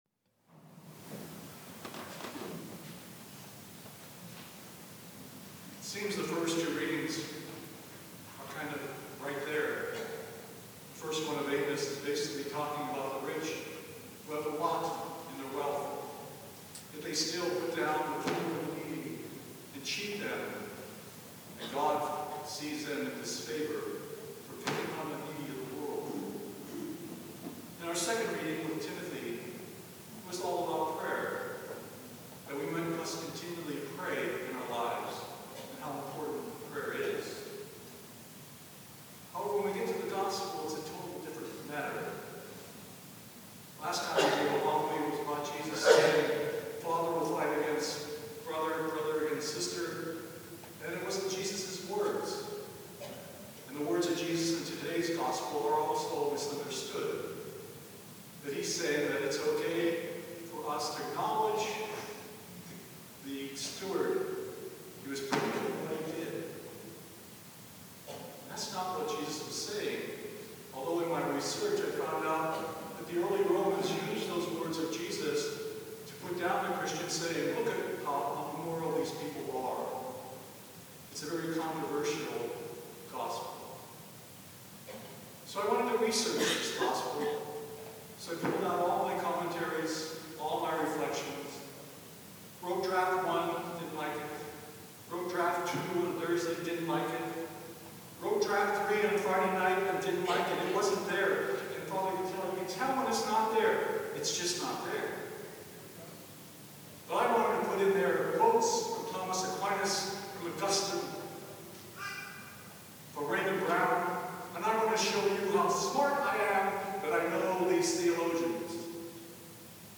Twenty Fifth Sunday in Ordinary Time
homily0920.mp3